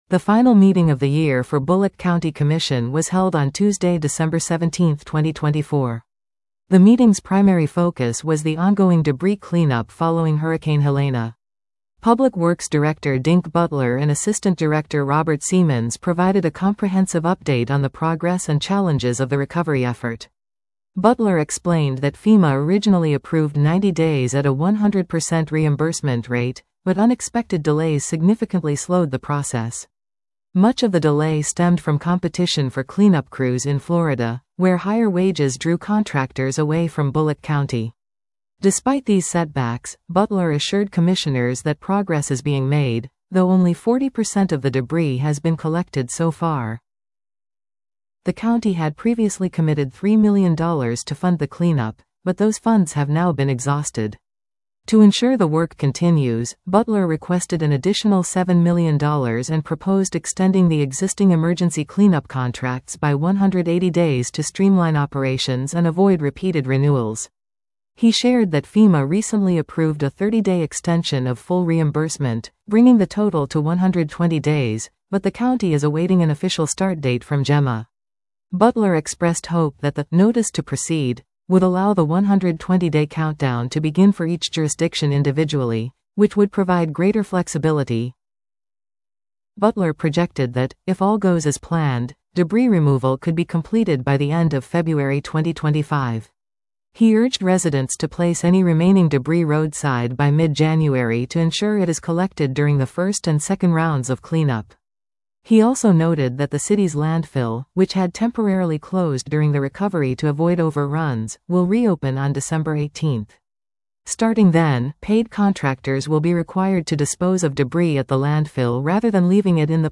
The final meeting of the year for Bulloch County Commission was held on Tuesday, December 17, 2024. The meeting’s primary focus was the ongoing debris cleanup following Hurricane Helene.